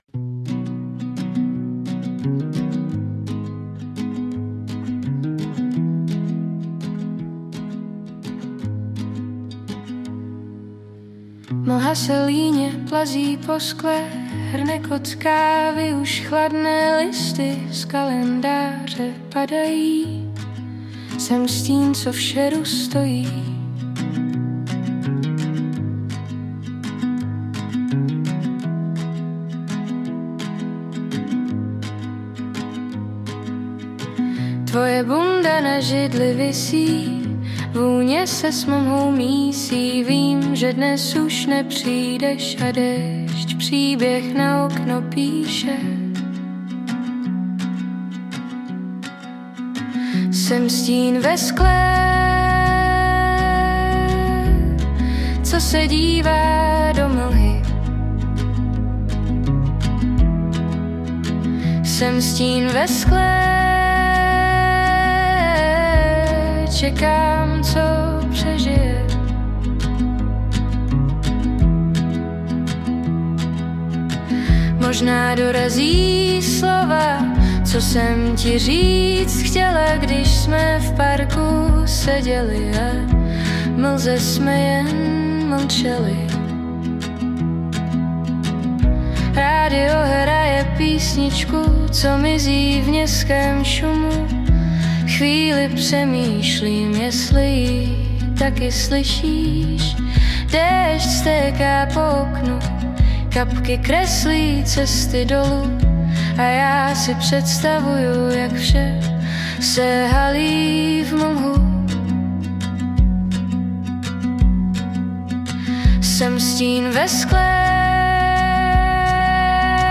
Zhudebnění opět provedla má dcera pomocí programů snad už brzo sladíme i živý zpěv. :)